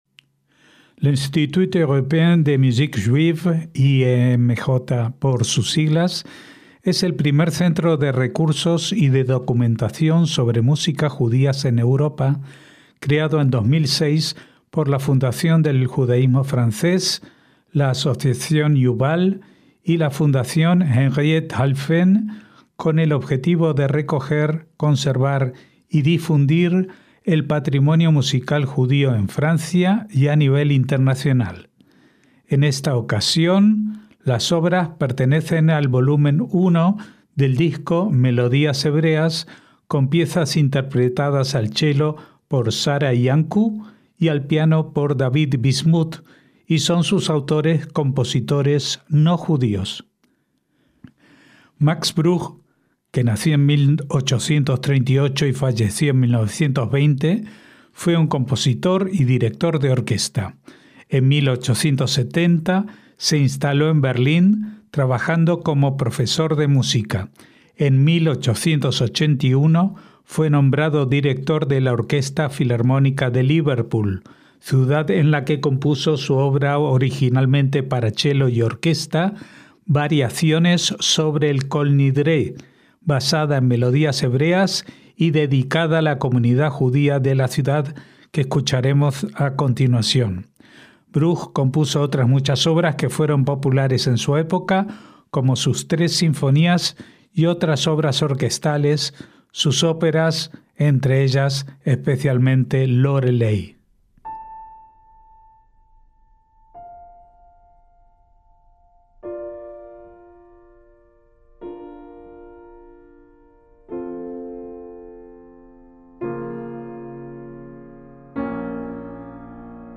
chelo
piano